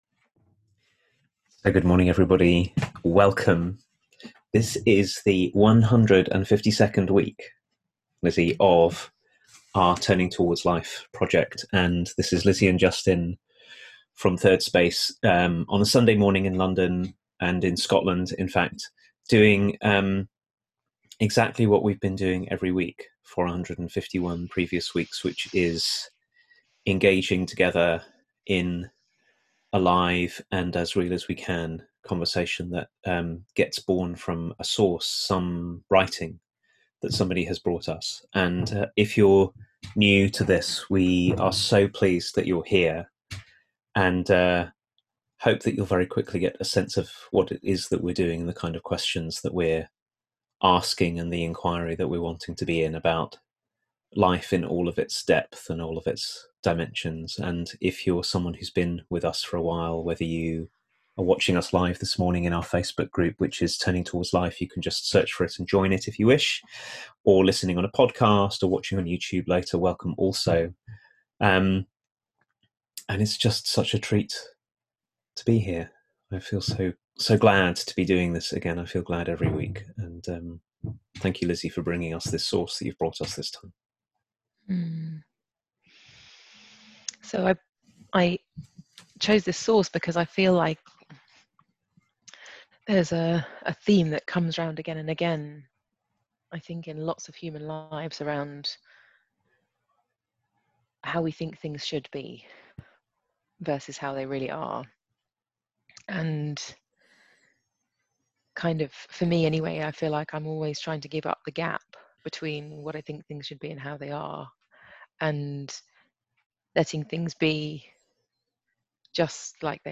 This is Turning Towards Life, a weekly live 30 minute conversation hosted by Thirdspace